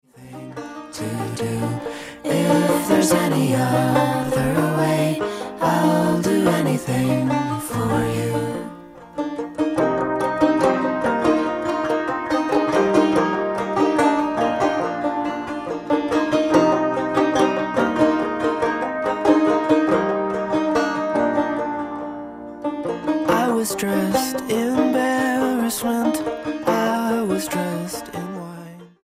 STYLE: Roots/Acoustic
Piano, banjo and trumpet